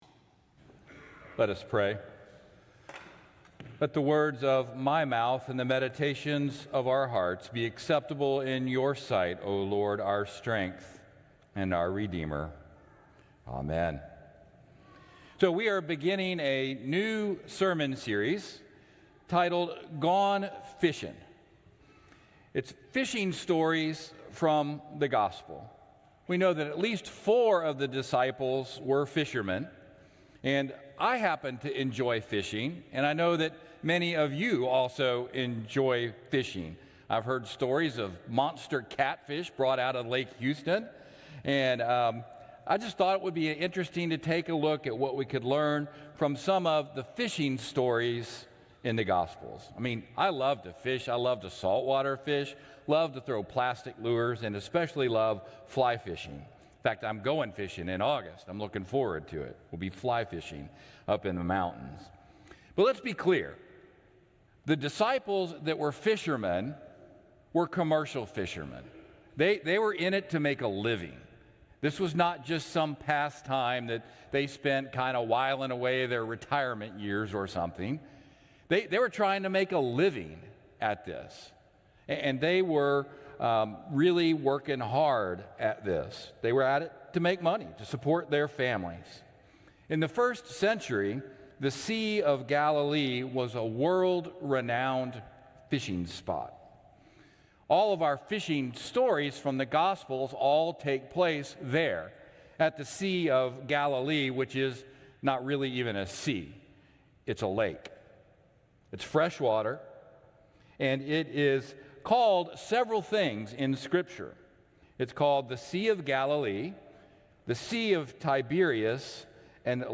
Traditional-Service-—-Jul.-9.mp3